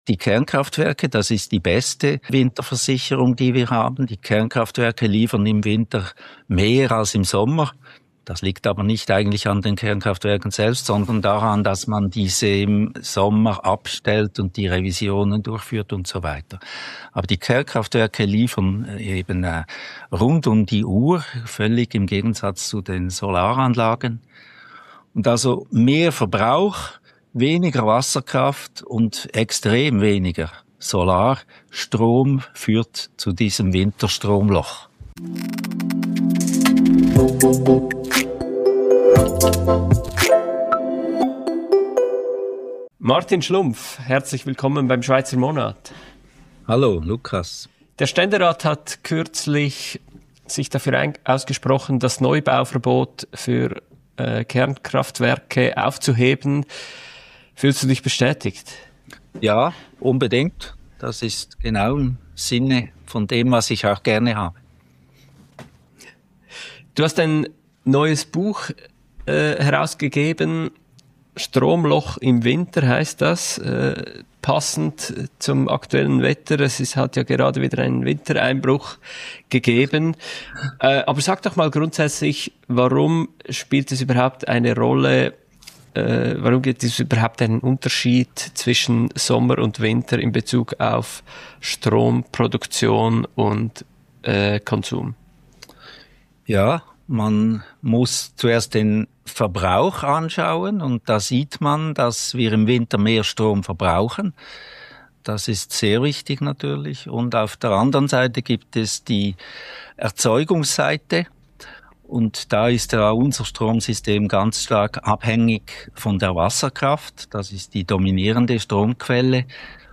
Und welche Lösungen gibt es überhaupt – von Kernkraft über erneuerbare Energien bis hin zu Importen? Das Gespräch beleuchtet die physikalischen, wirtschaftlichen und politischen Hintergründe der Schweizer Energiezukunft – verständlich erklärt und mit klaren Positionen.